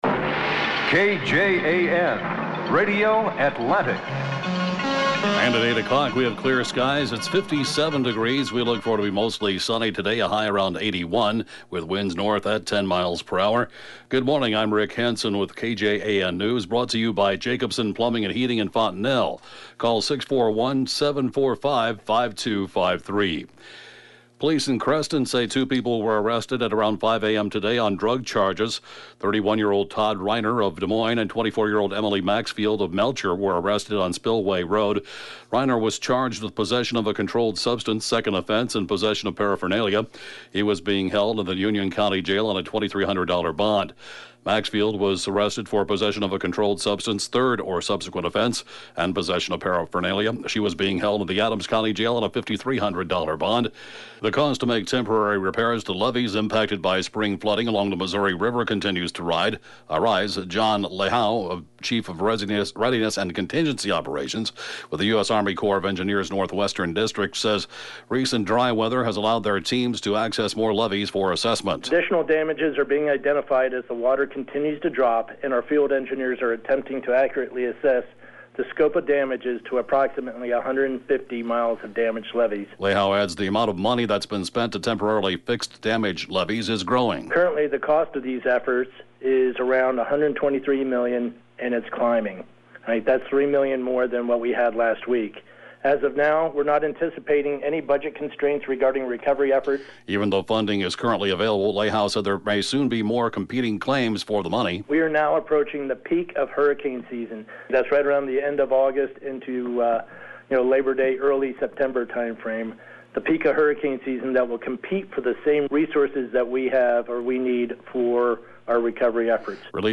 (Podcast) KJAN Morning News & funeral report, 4/6/2018